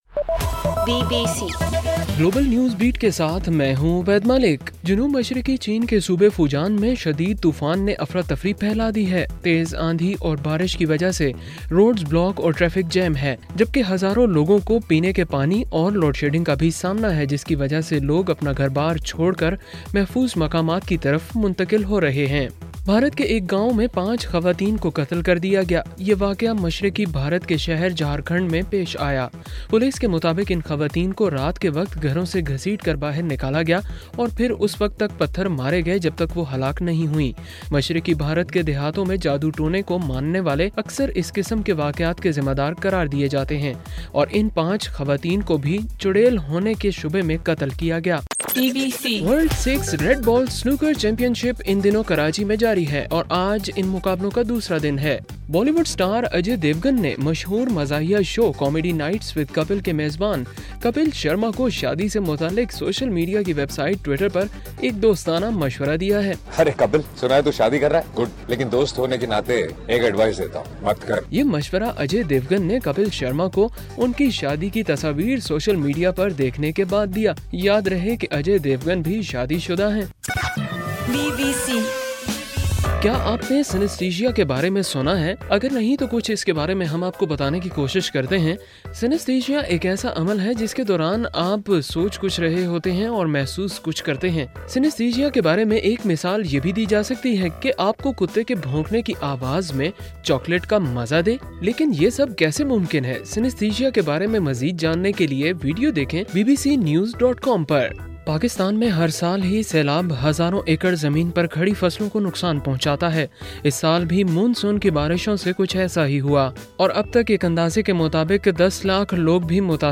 اگست 9: صبح 1 بجے کا گلوبل نیوز بیٹ بُلیٹن